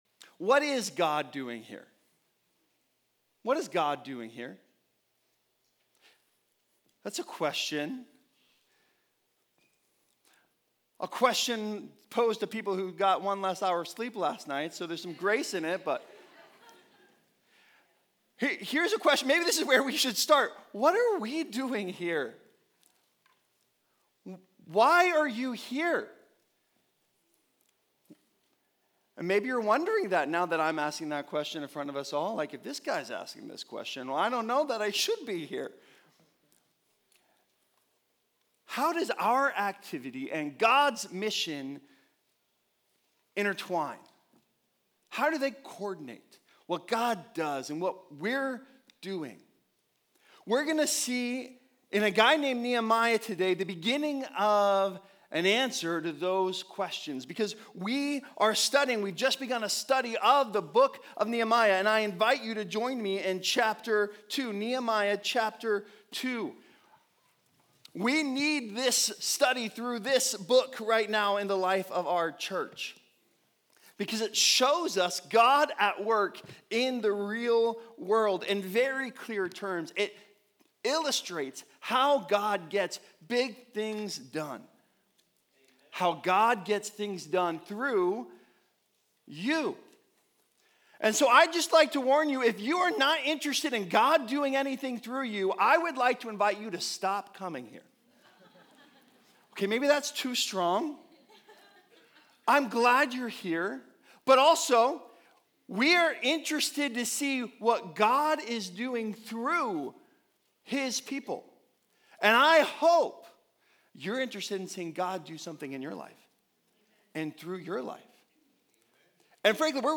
Nehemiah 2 | Our Activity in God's Mission | Nehemiah - HP Campus Sermons